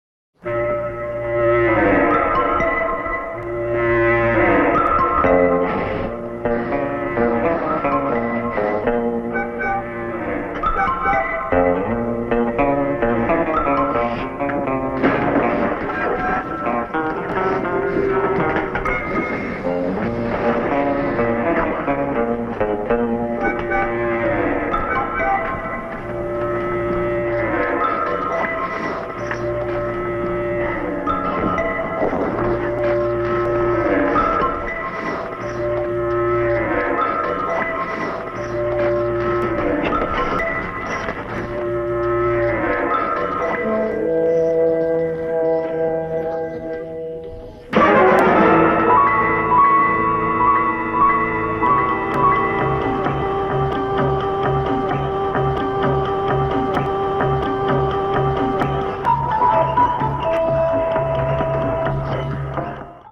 The bouncy score